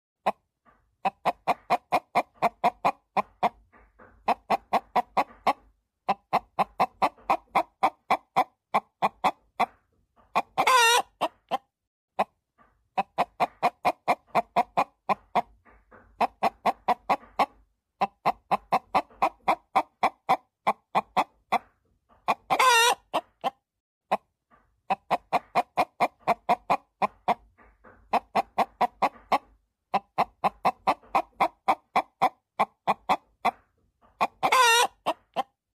Genre: Nada dering alarm